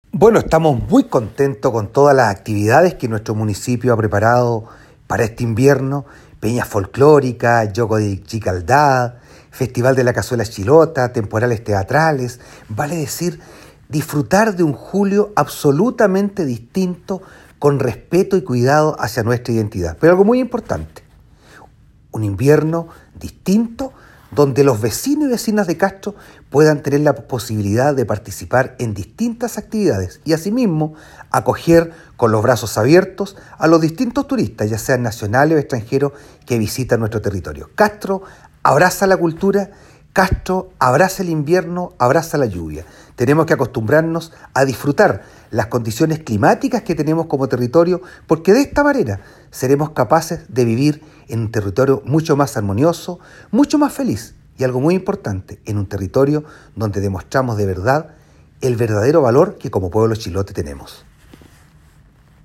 CUÑA-ALCALDE-PANORAMAS-INVIERNO.mp3